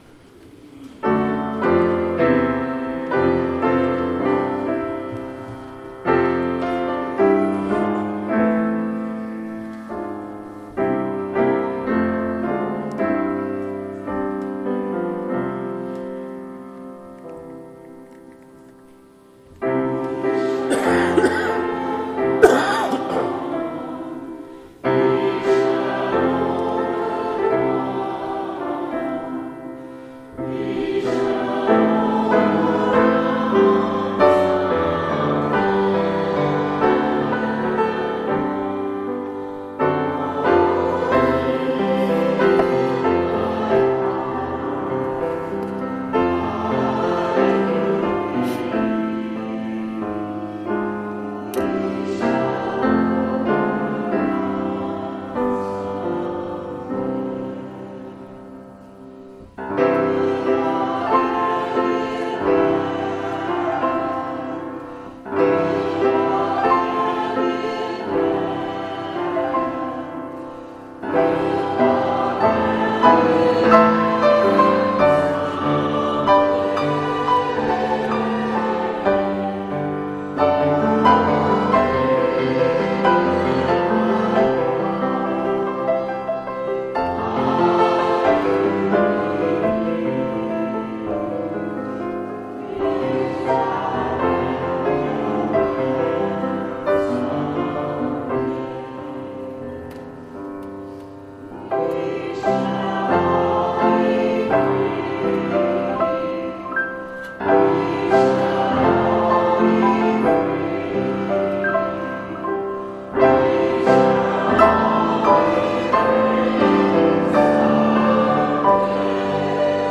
Congregational hymn.